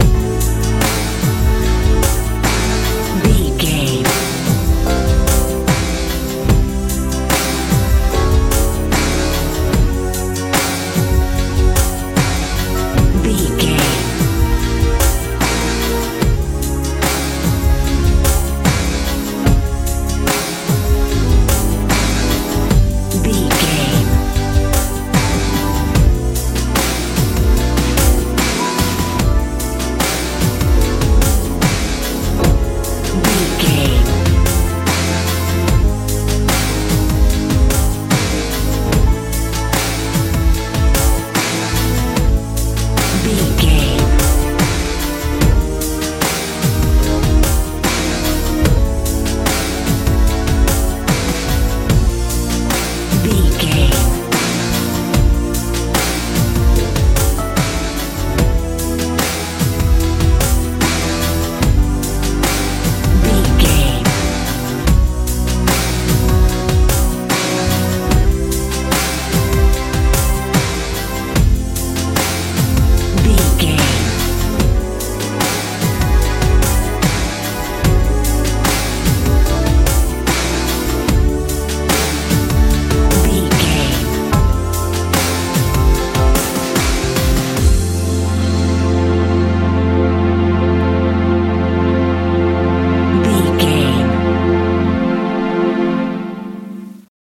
retro pop feel
Ionian/Major
Slow
futuristic
powerful
synthesiser
piano
drums
bass guitar
tension
suspense